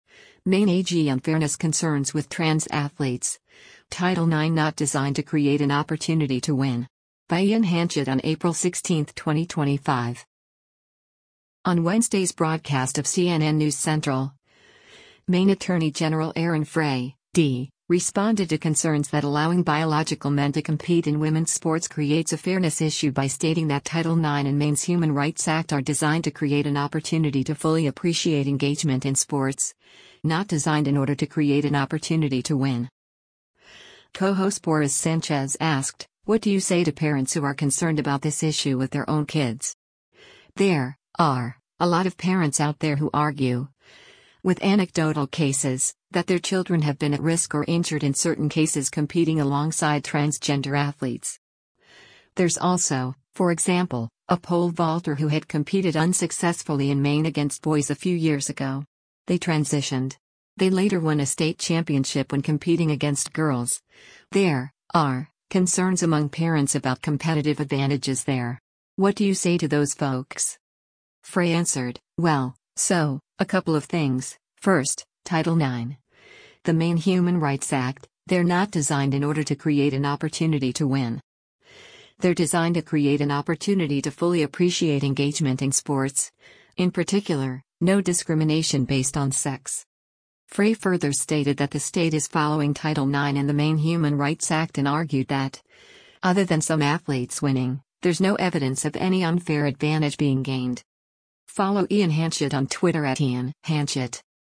On Wednesday’s broadcast of “CNN News Central,” Maine Attorney General Aaron Frey (D) responded to concerns that allowing biological men to compete in women’s sports creates a fairness issue by stating that Title IX and Maine’s Human Rights Act are “designed to create an opportunity to fully appreciate engagement in sports,” “not designed in order to create an opportunity to win.”